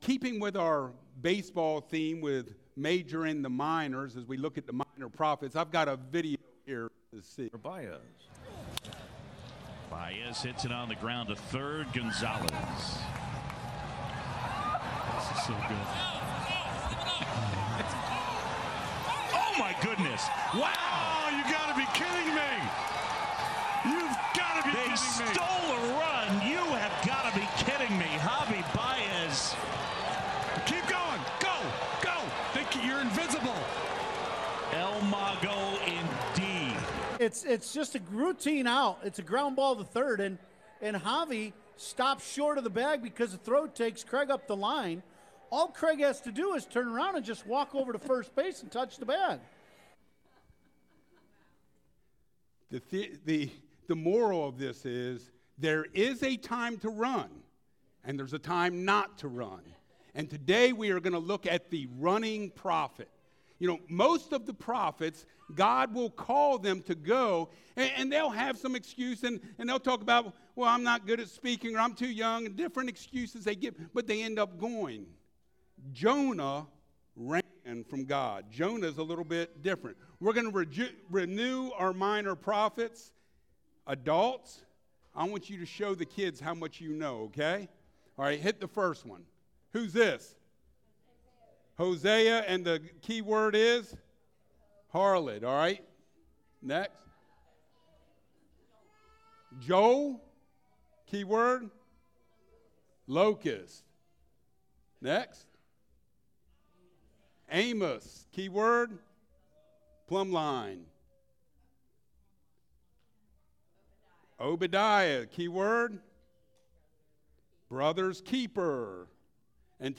Service Type: Sunday Mornings